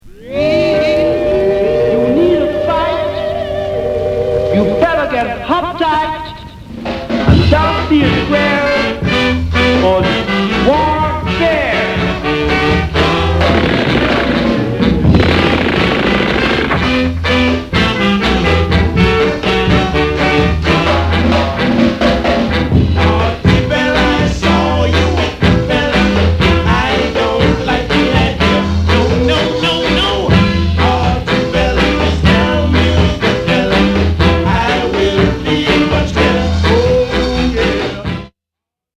イントロからマシンガンの銃声SEが鳴りまくり！！
こんなに攻撃的な１枚で盛り上がらない人はいません！！